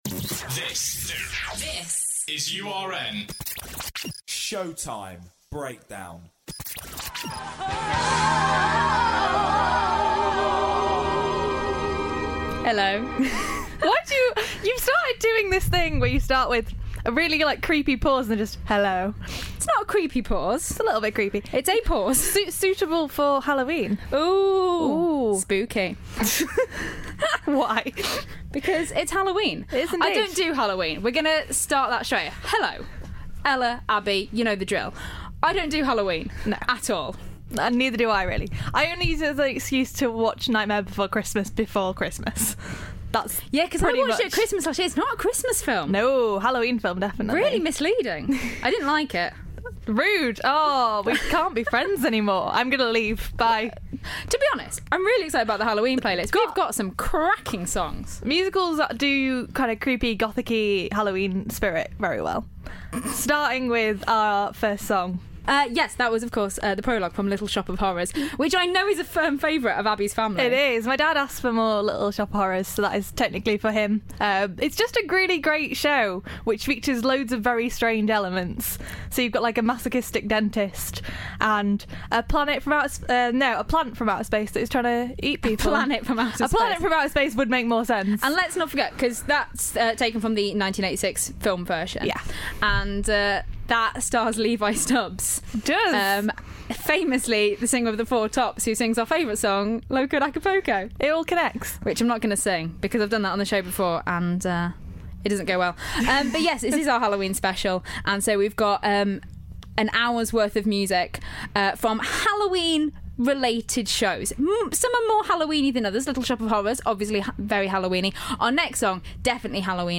It's Halloween (well, near enough) and what better way to celebrate than with some spooky show tunes!